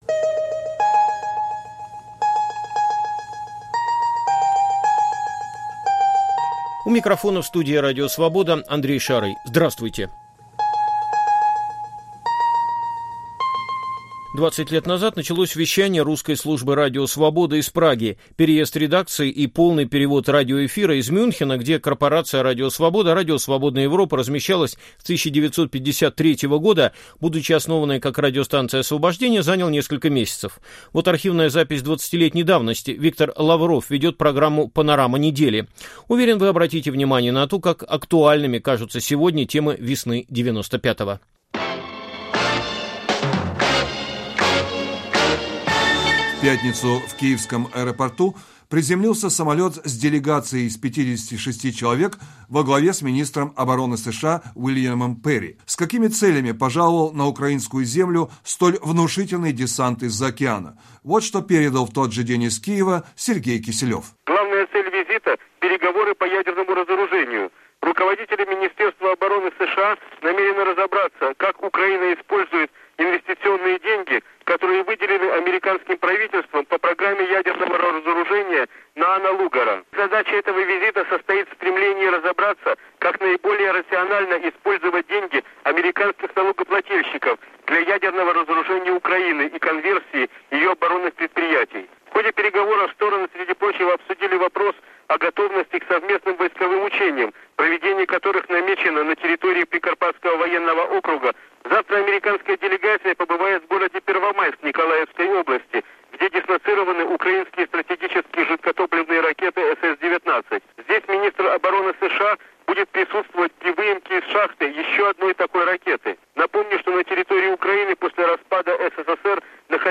Звучат архивные аудиозаписи